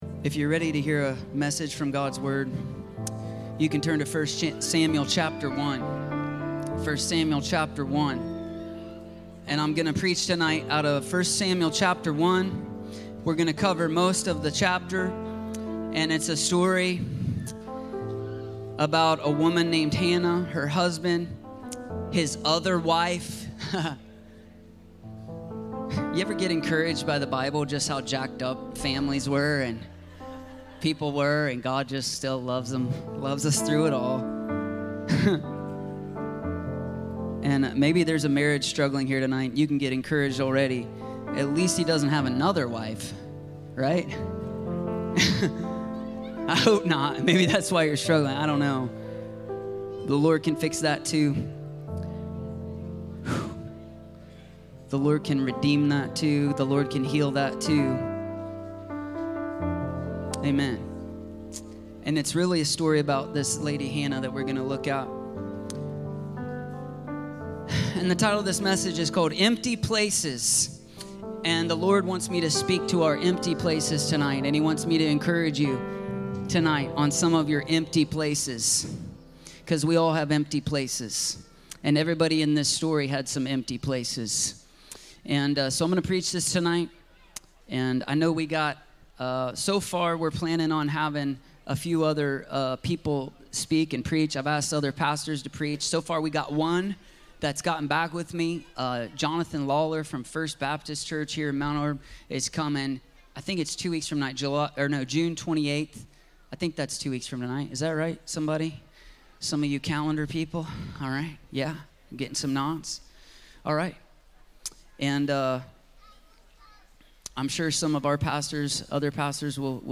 Empty Places - Stand Alone Messages ~ Free People Church: AUDIO Sermons Podcast